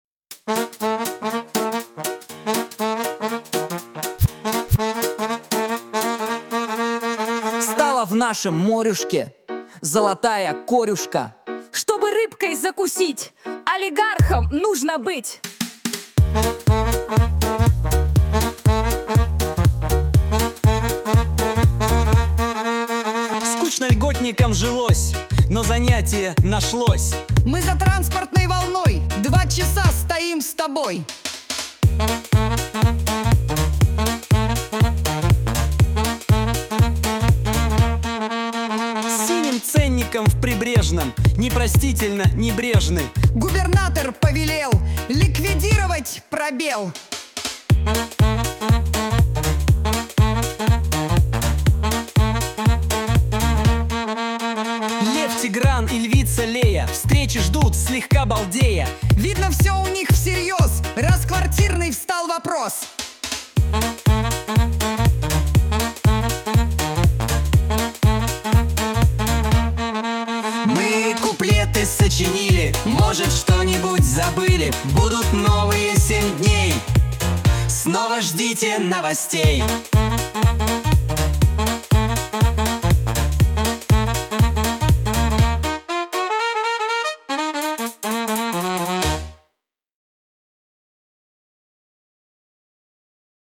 О главных и важных событиях — в виде комических куплетов